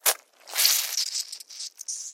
Звук укуса пиявки когда она сосет кровь